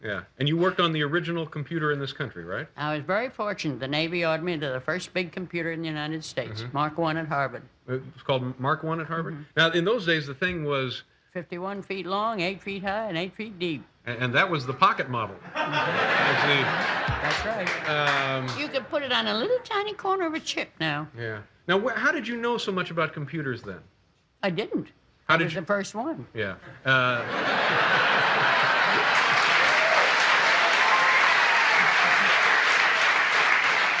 2-two-speakers-en.wav